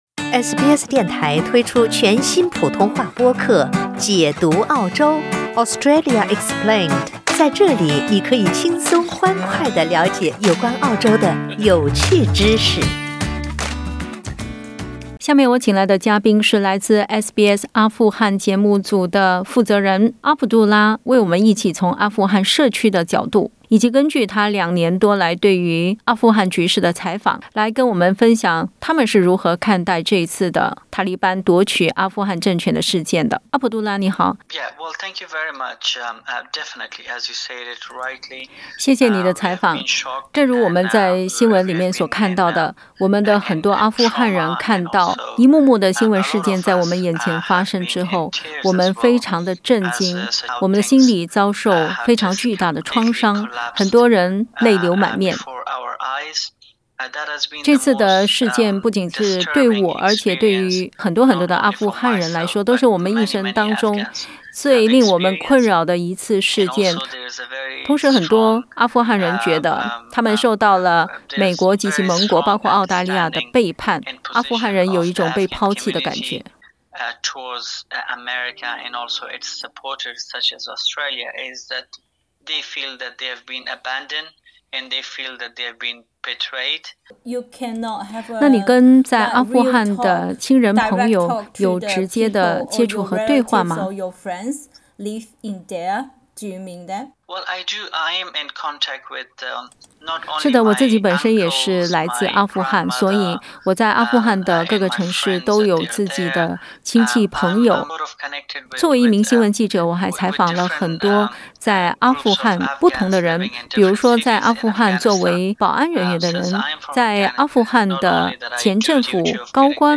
阿富汗再次被塔利班占领，海内外阿富汗人民如何看待这场发生在自己祖国的突变？（点击图片收听采访）